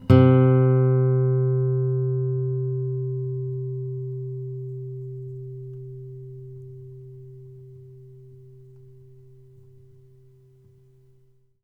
bass-02.wav